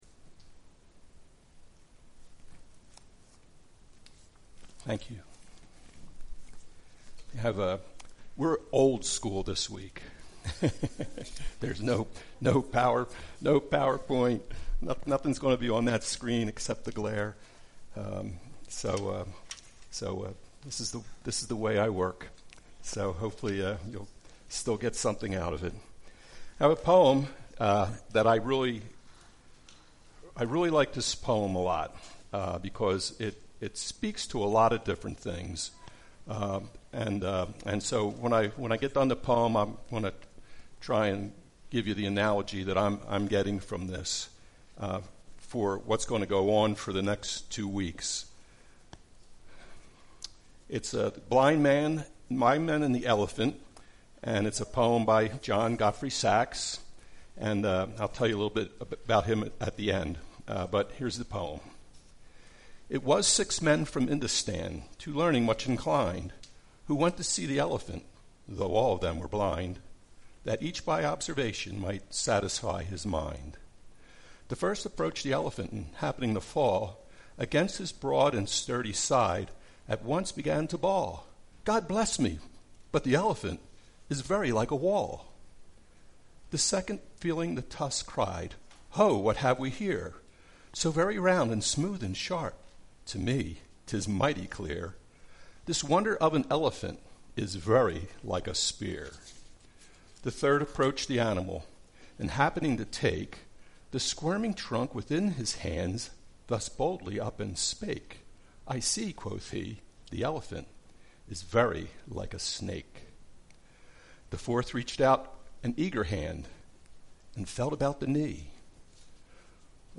The Word Service Type: Sunday Speaker